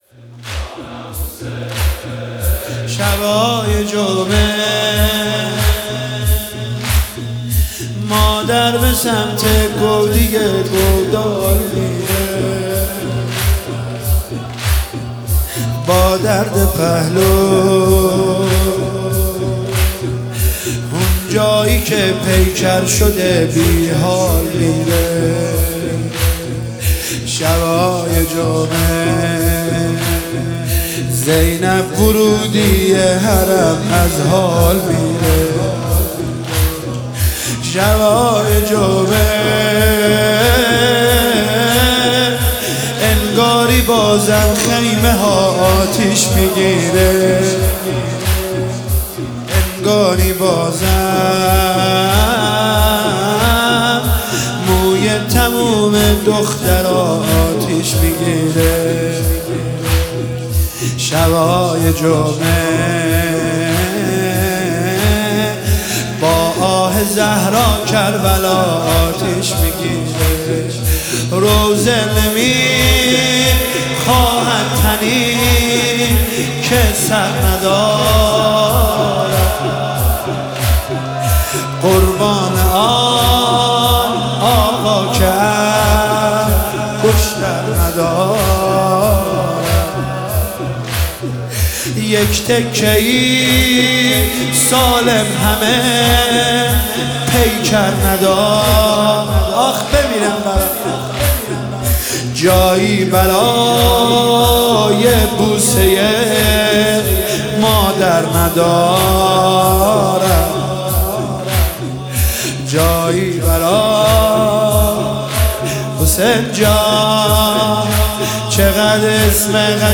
دانلود مداحی زمینه